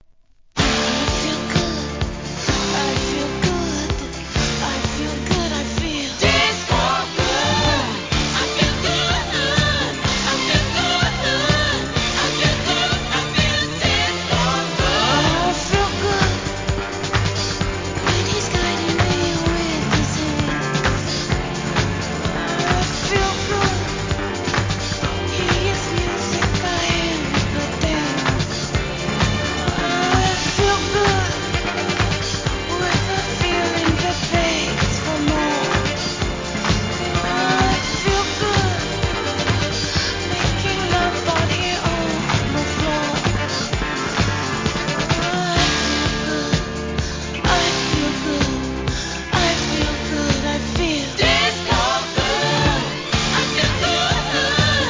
SOUL/FUNK/etc... 店舗 数量 カートに入れる お気に入りに追加 1978年ディズコ賛歌!